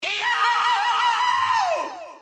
Peppino Scream
Perfect For Unblocked Sound Buttons, Sound Effects, And Creating Viral Content.